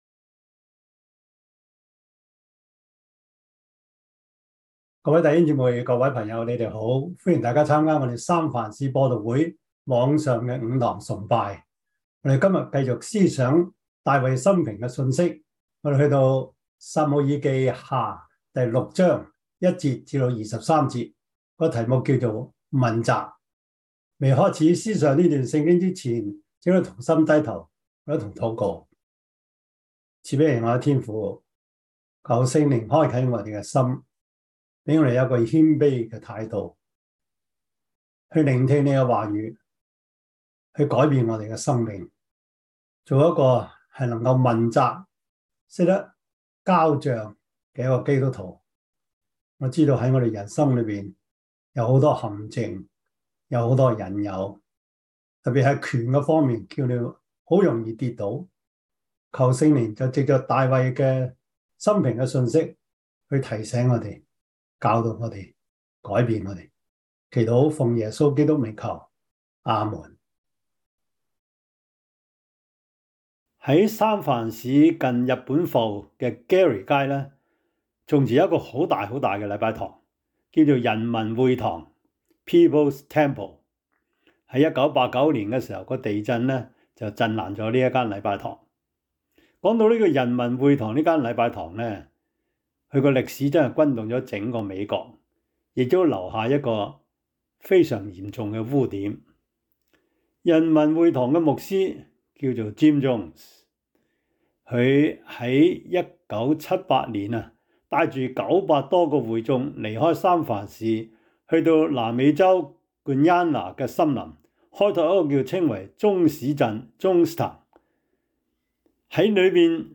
撒母耳記下 6:1-23 Service Type: 主日崇拜 撒母耳記下 6:1-23 Chinese Union Version
Topics: 主日證道 « 人到三十 跟官咁耐唔知官性乜 »